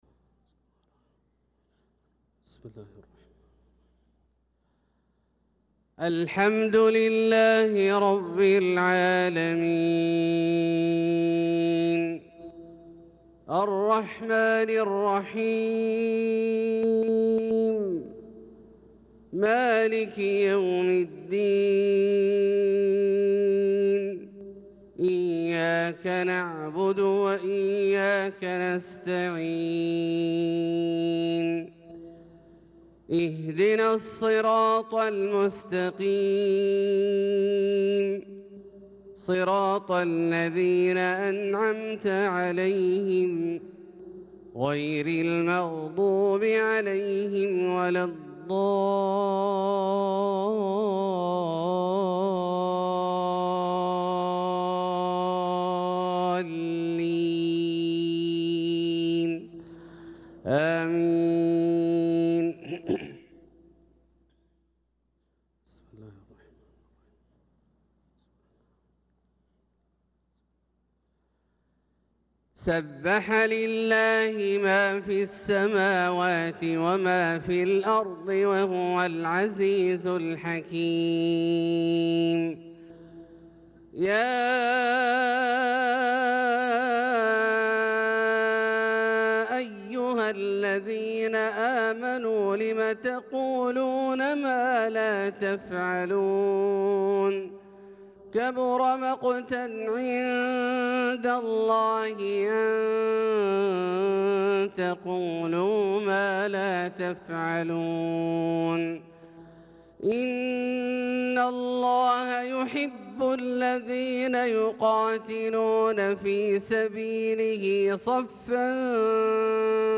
صلاة الفجر للقارئ عبدالله الجهني 17 ذو القعدة 1445 هـ
تِلَاوَات الْحَرَمَيْن .